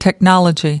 7. technology (n) /tekˈnɒlədʒi/: công nghệ, kĩ thuật